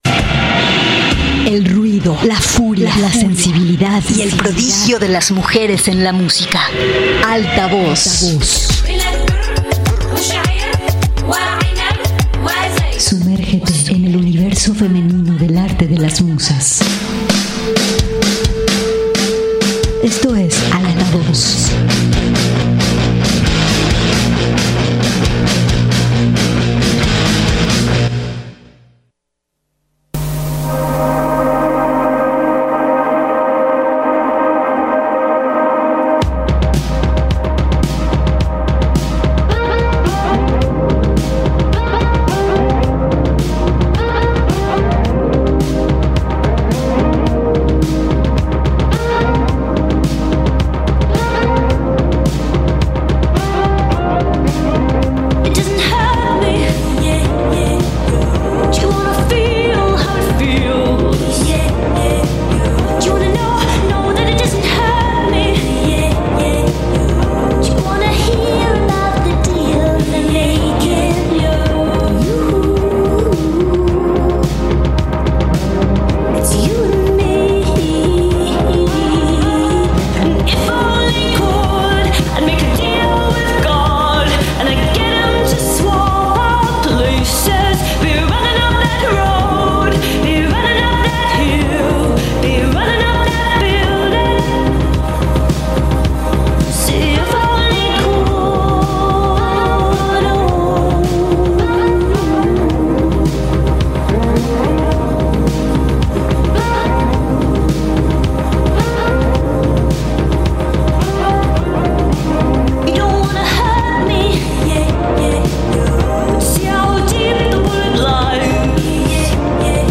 El ruido, la furia, la sensibilidad y el prodigio de las mujeres en la música y la literatura, se hacen presentes todos los miércoles a las 7:00 de la tarde, por el 104.7 de FM, Radio Universidad de Guadalajara en Colotlán.